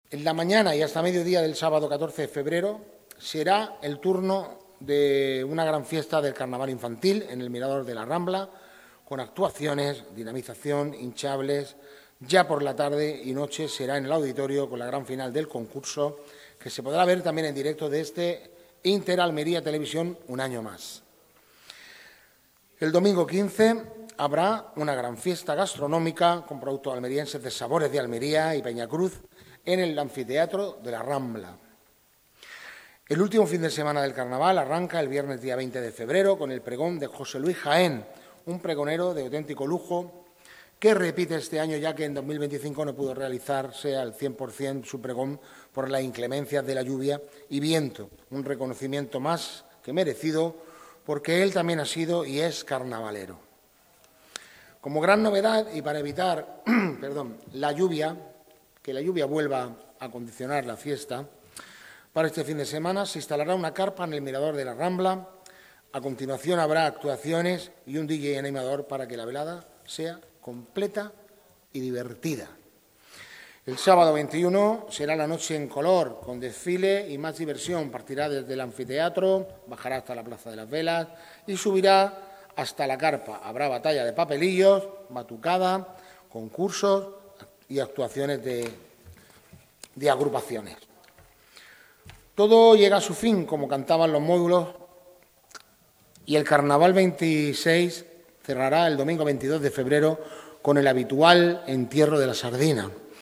DIEGO-CRUZ-CONCEJAL-CULTURA-PRESENTACION-CARNAVAL-2026.mp3